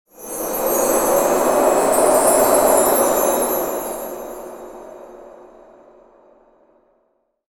Fast Cinematic Wind And Chimes Transition Sound Effect
Description: Fast cinematic wind and chimes transition sound effect. Add magic to your videos and Christmas projects with this enchanting transition sound effect.
Use this whimsical sound to create festive, magical, and cinematic moments.
Fast-cinematic-wind-and-chimes-transition-sound-effect.mp3